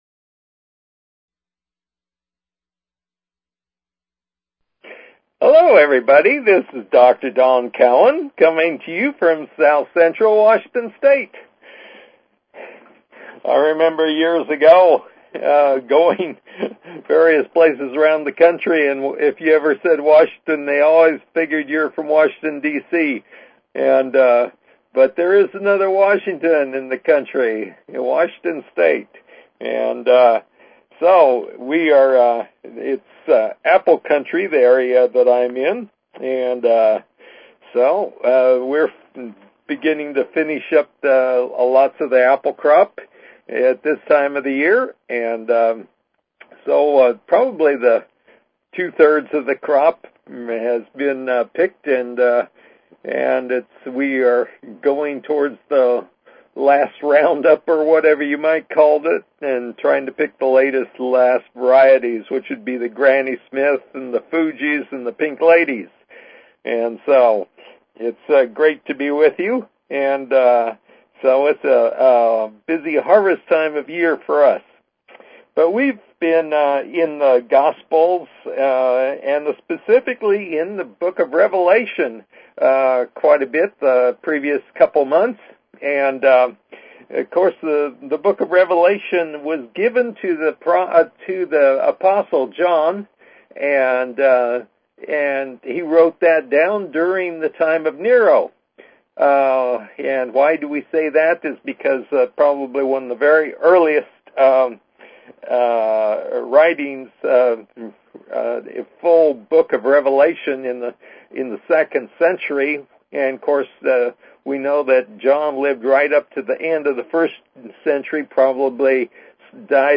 Talk Show Episode, Audio Podcast, New_Redeaming_Spirituality and Courtesy of BBS Radio on , show guests , about , categorized as